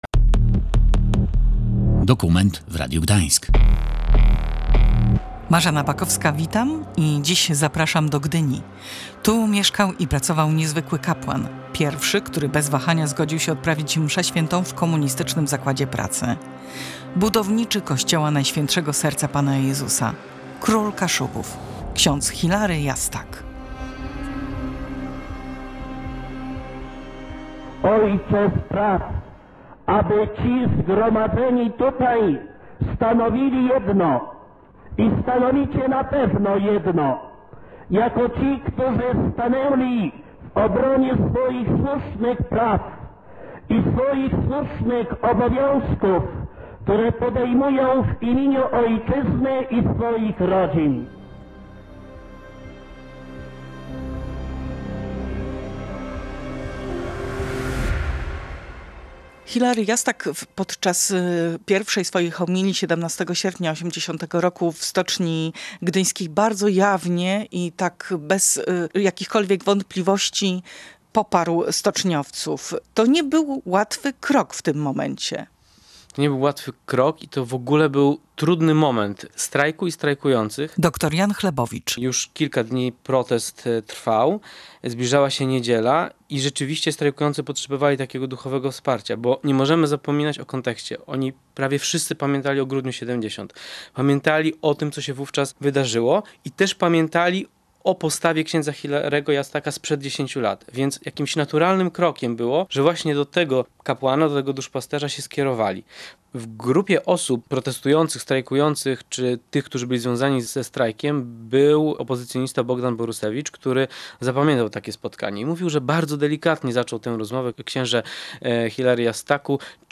z archiwalnych nagrań z czasu strajku w Stoczni im. Komuny Paryskiej w Gdyni.